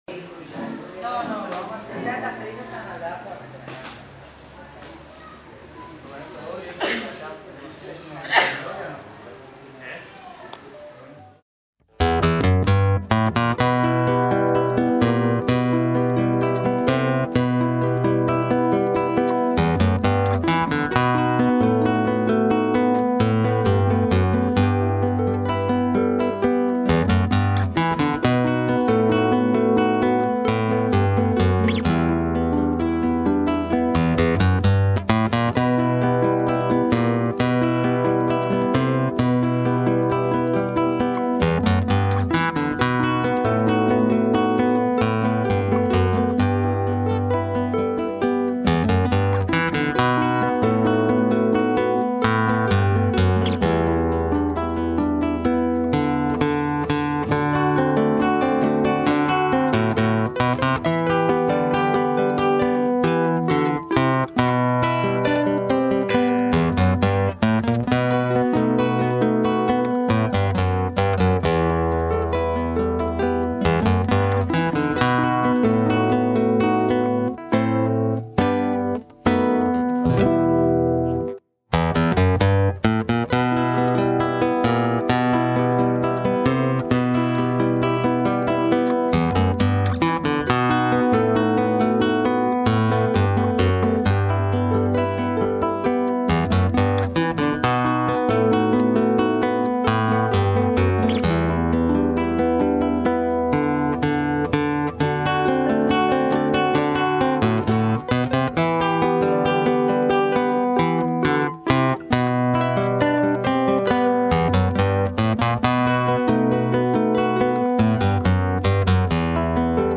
Carpeta: Folklore mp3
guitarras